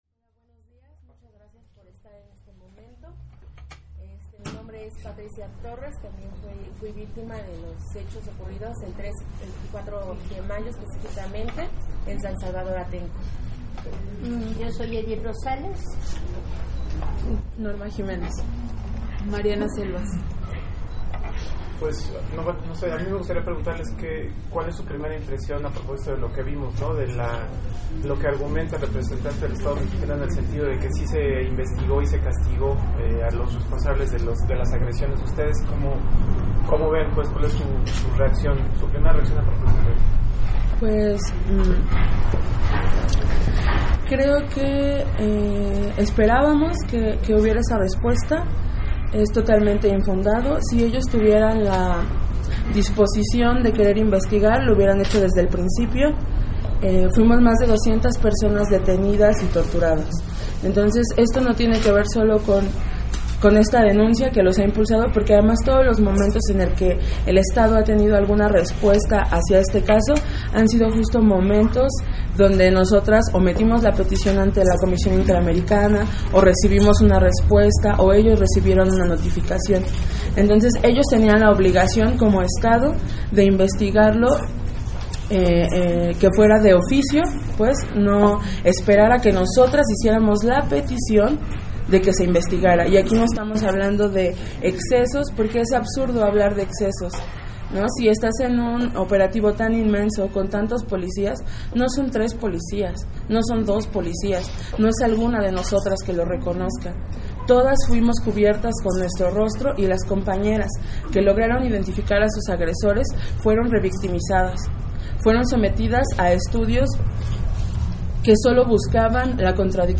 Conferencia_de_Prensa.mp3